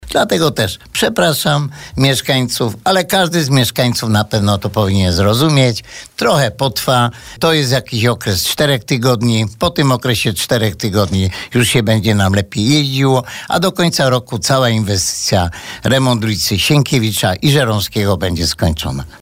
– Przepraszam mieszkańców, ale jednocześnie uważam, że każdy powinien to zrozumieć. Prace potrwają około cztery tygodnie, po tym czasie będzie się nam jeździło już lepiej. A do końca roku cała inwestycja, czyli remont zarówno ulicy Żeromskiego jak i Sienkiewicza będzie skończona – deklarował dzisiaj na naszej antenie burmistrz Antoni Szlagor.